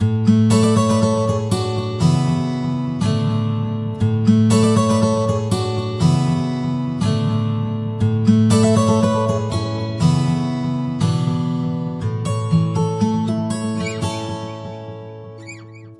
GuitarStrum » A Maj
描述：simple guitar strum pattern, 4/4 time, on a Fender Stratocaster, clean with light chorus pack contains each of twelve chords (E D) played in both Major and Minor
标签： 120bpm guitar strum